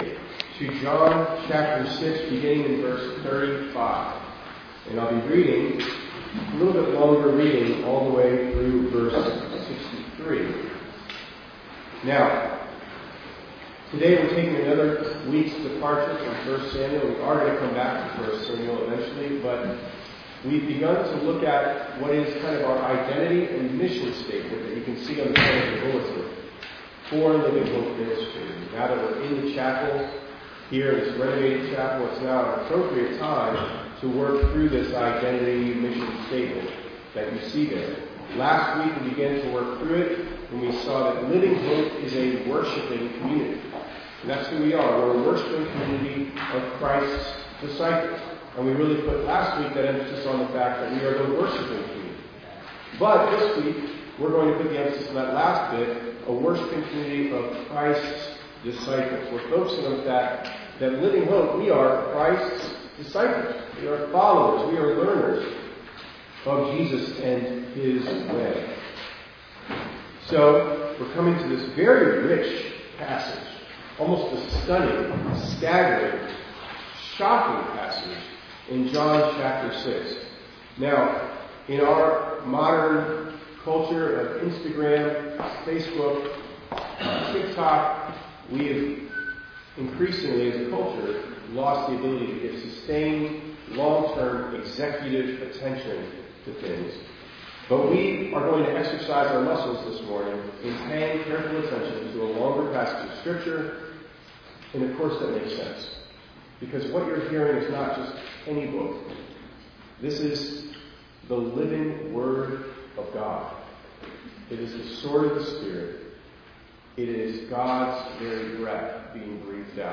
2_22_26_ENG_Sermon.mp3